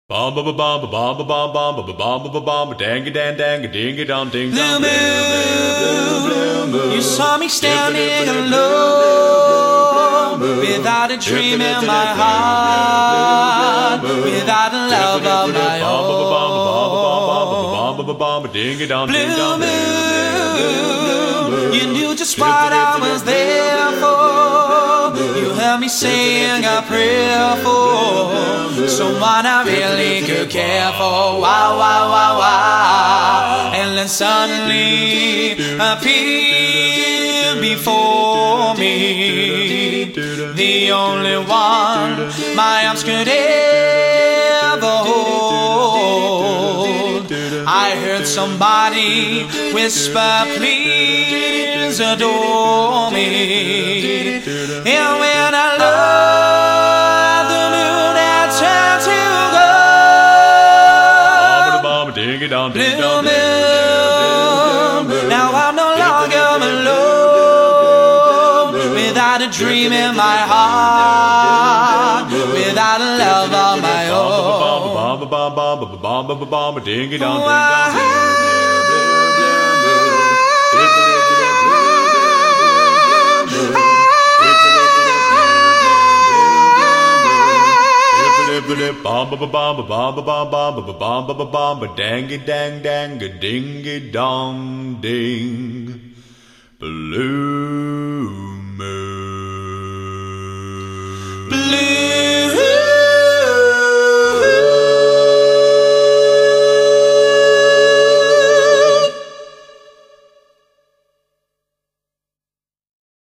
With four outstanding lead vocals and close harmonies